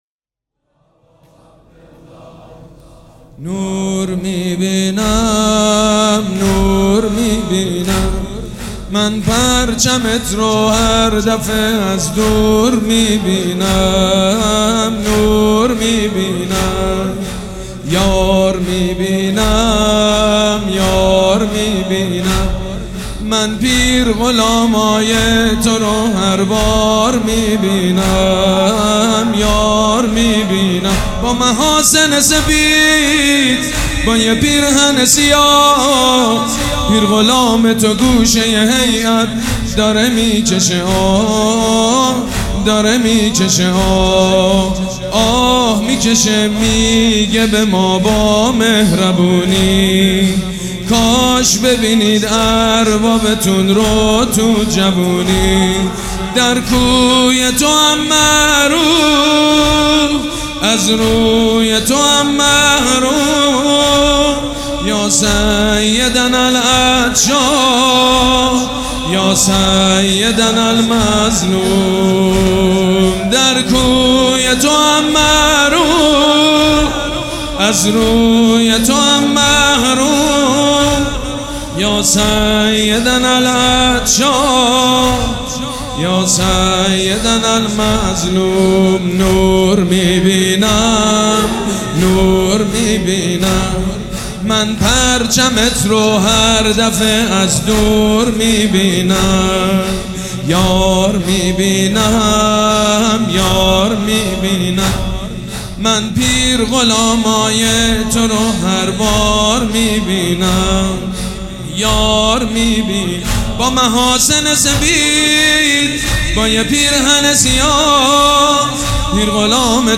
حاج سید مجید بنی فاطمه
مراسم عزاداری شب سوم